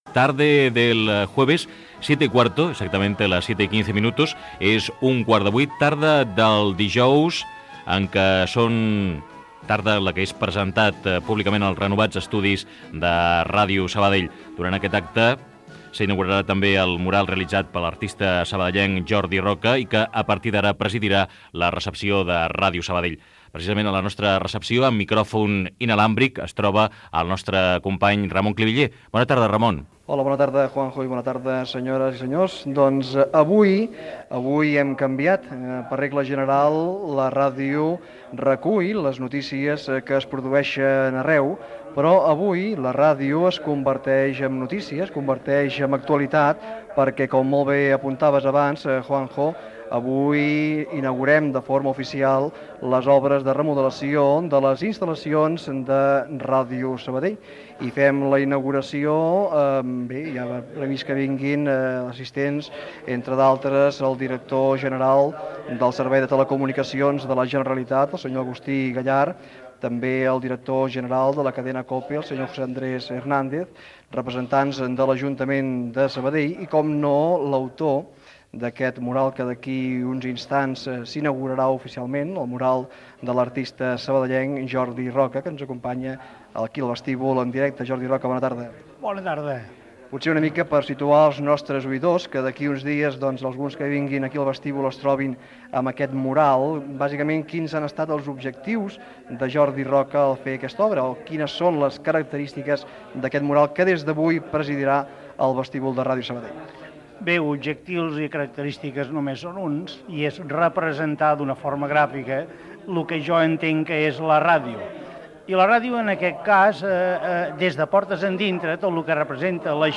Edició especial amb motiu de la remodelació dels estudis de Ràdio Sabadell.
Entreteniment